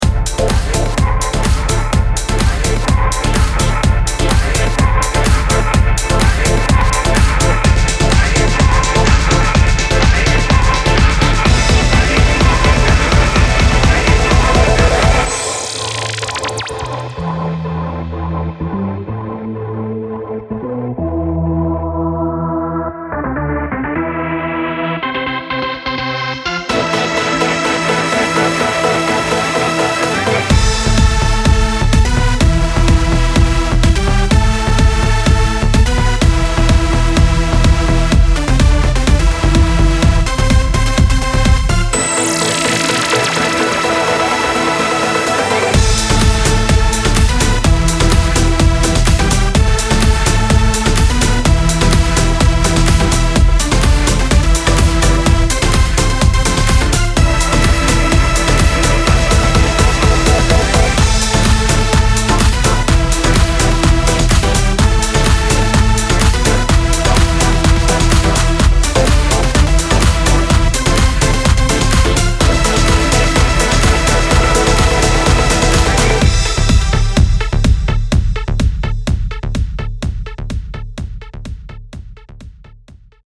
Vocals
Keyboards
Drums